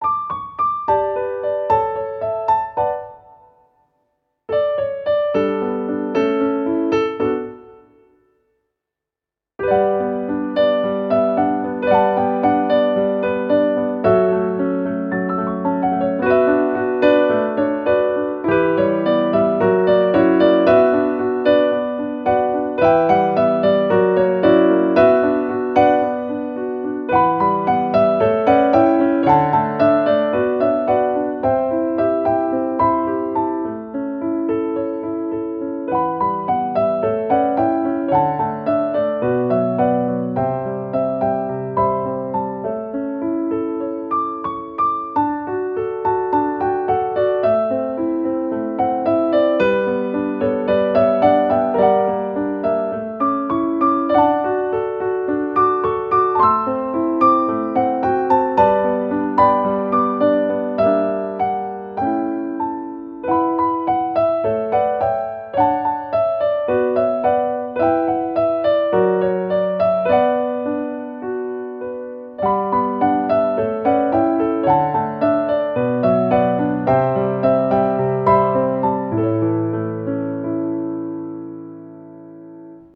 ogg(R) - 甘酸っぱい 切ない メロディアス
果実のようにみずみずしい旋律。